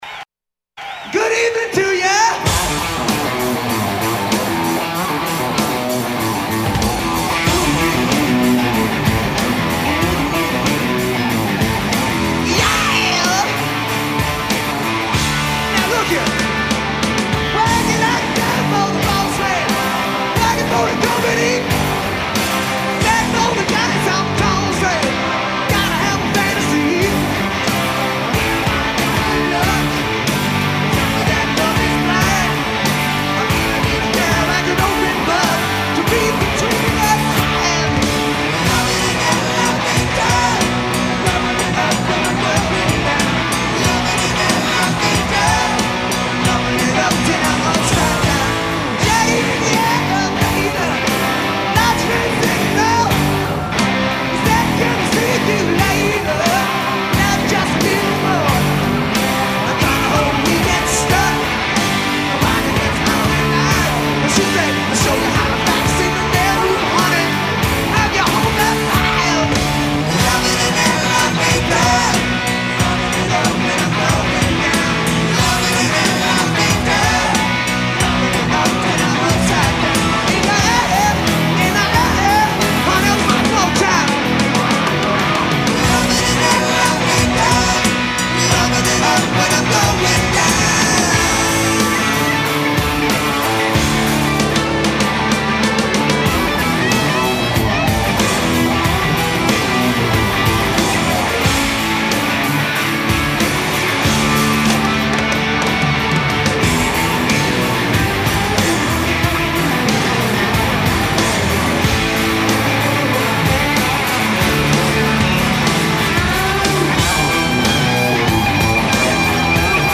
in concert
sizzling live version